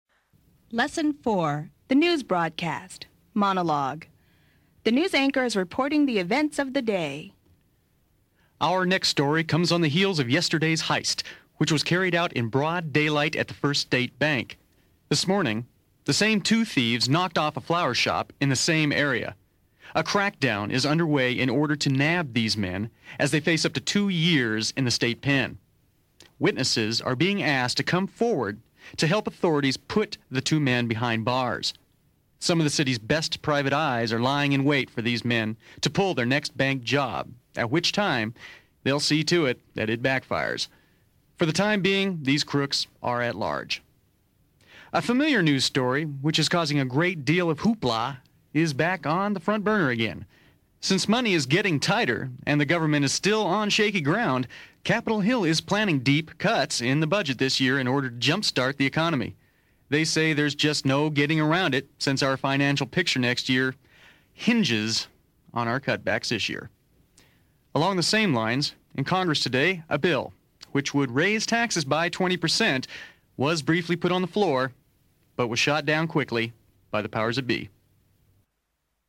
The News Broadcast
The news anchor is reporting the events of the day.